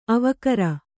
♪ avakara